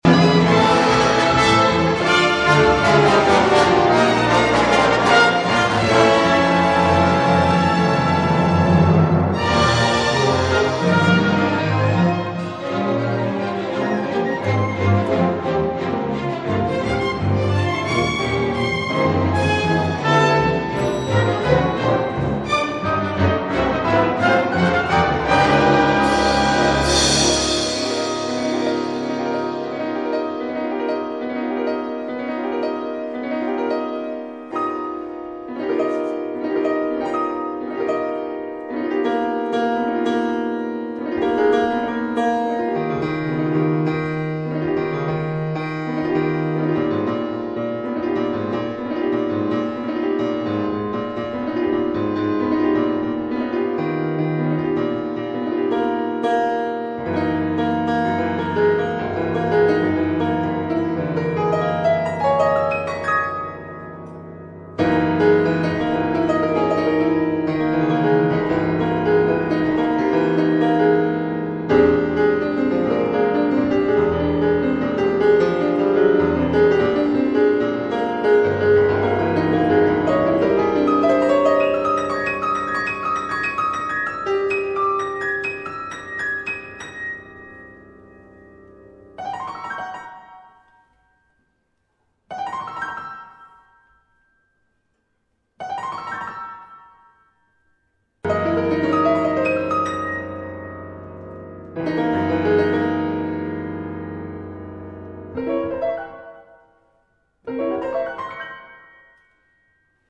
für Orchester und improvisiertes Klavier